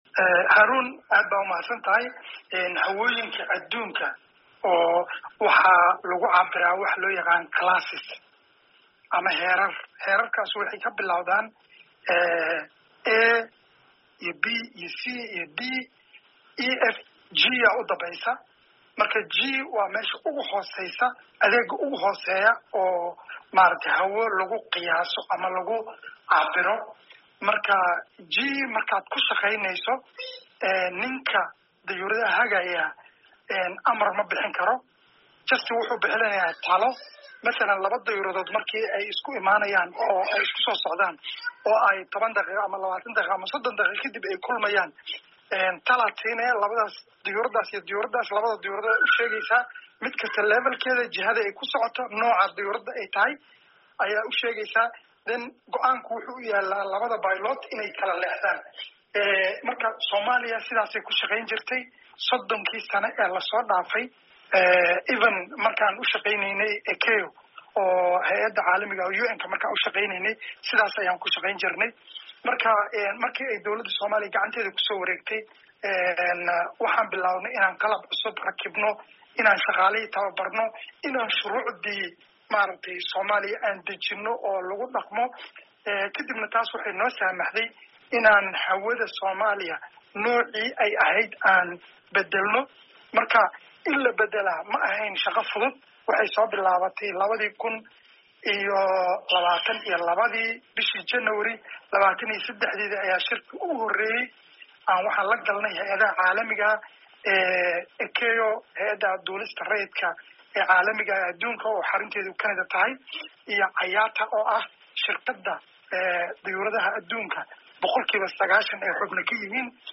Waraysiga: Agaasimaha Duulista Rayidka ah